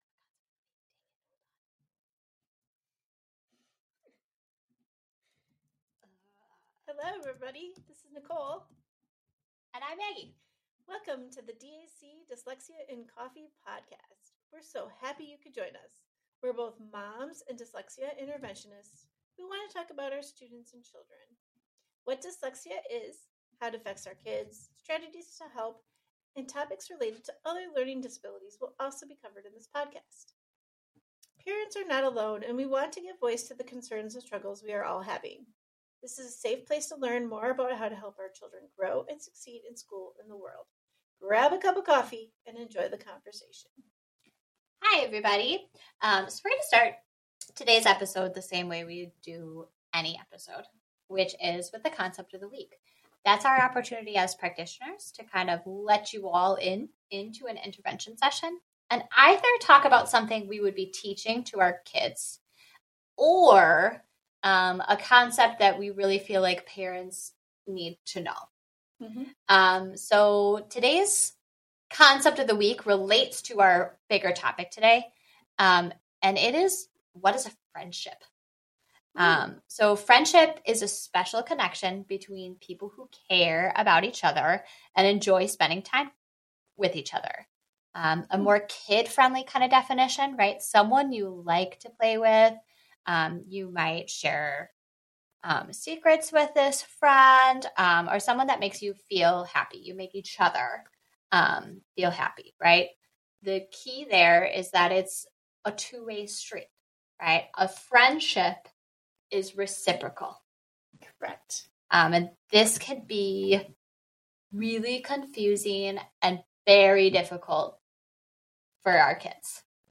We are both moms and dyslexia interventionists who want to talk about our students and children.